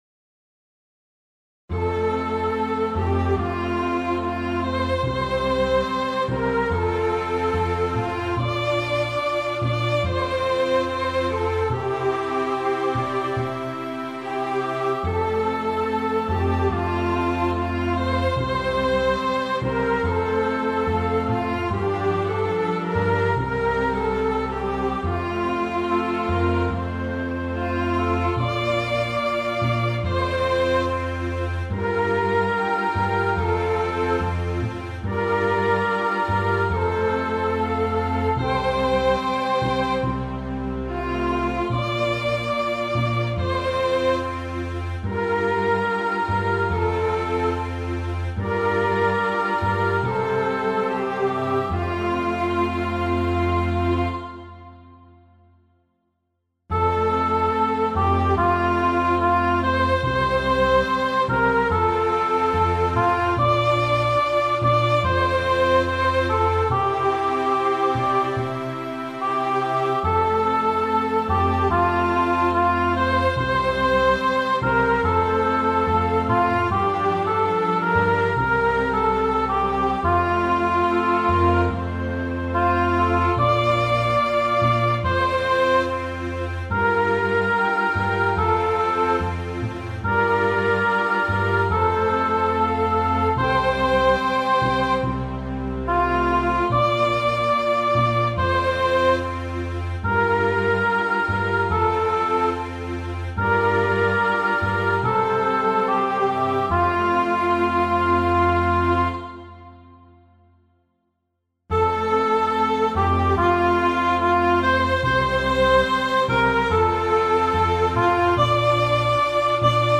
key of F